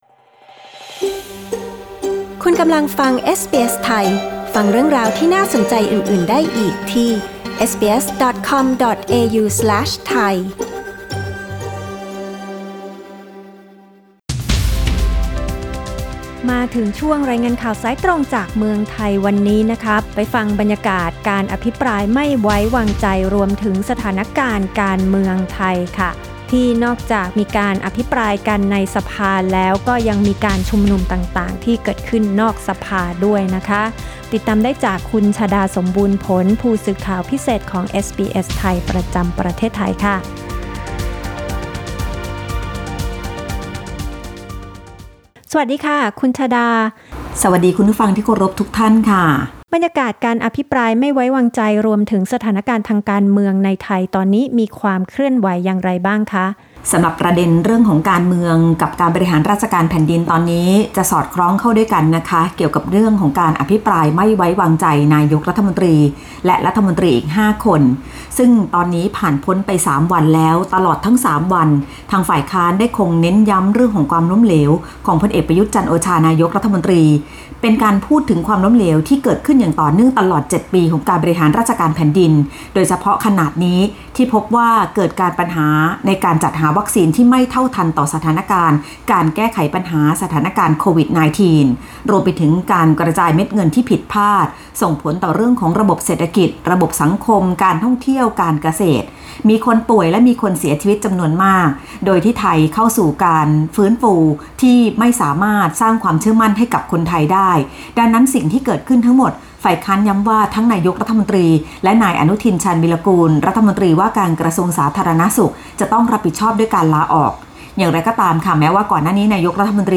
ฟังรายงานข่าว